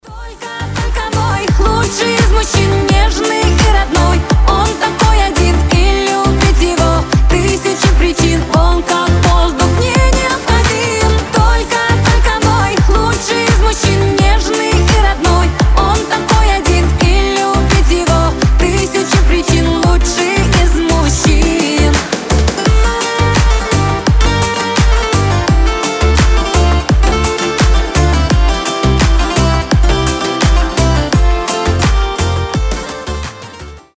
романтичные
женский голос шансон